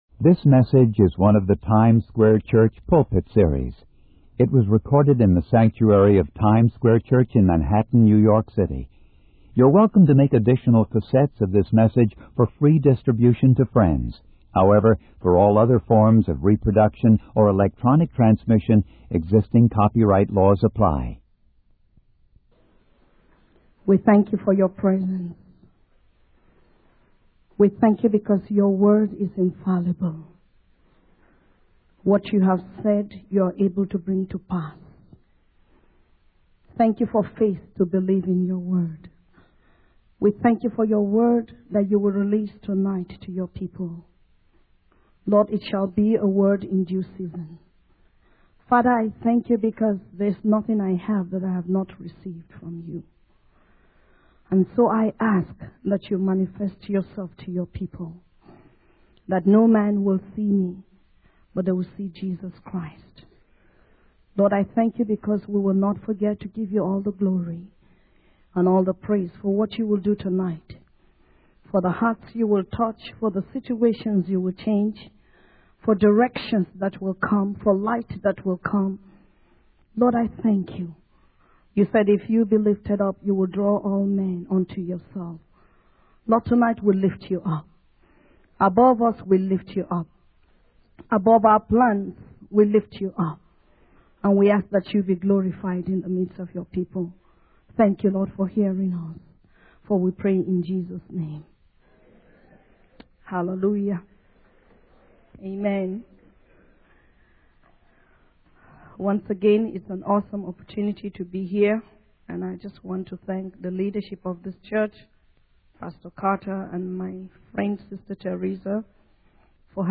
In this sermon, the preacher discusses the difference between what we want and what we truly need. He starts by reading from the book of James, highlighting how our desires for pleasure can lead to conflicts and wars.